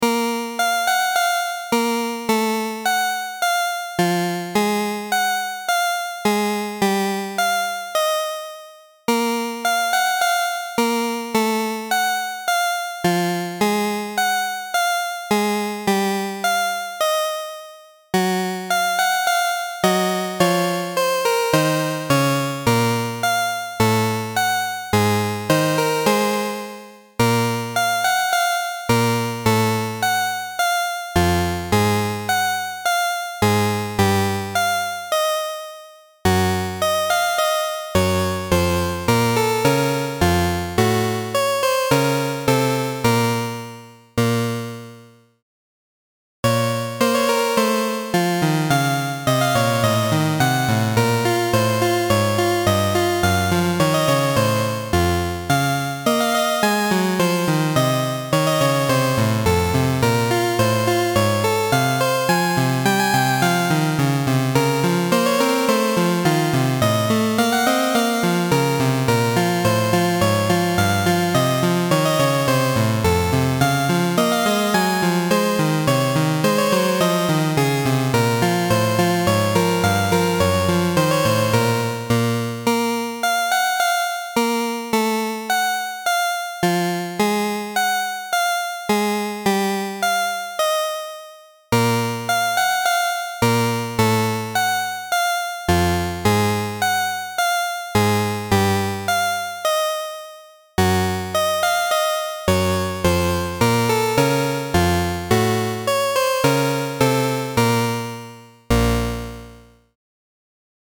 bgm.mp3